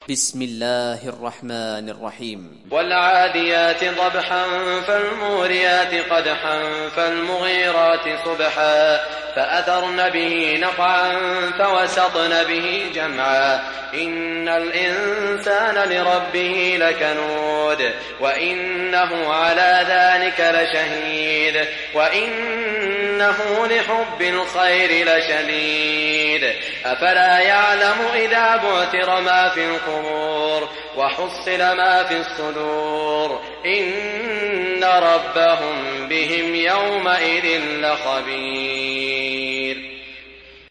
Surah Al Adiyat mp3 Download Saud Al Shuraim (Riwayat Hafs)